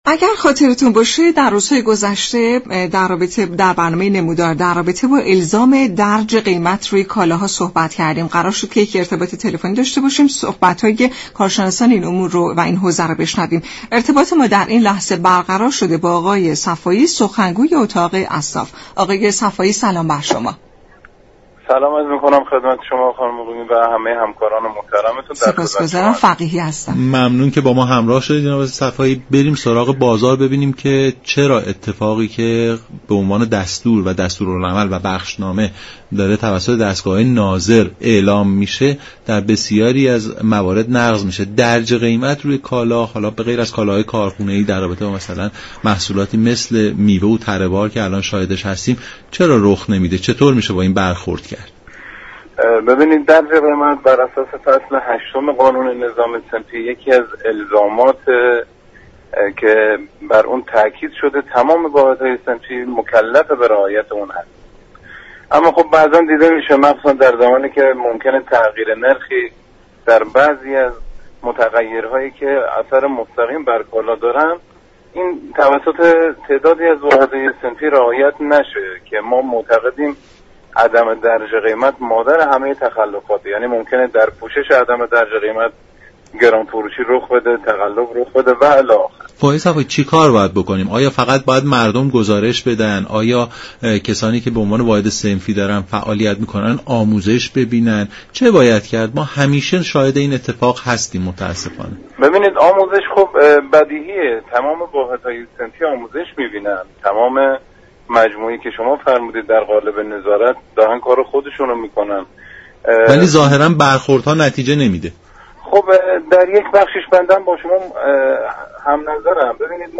گفت و گوی رادیویی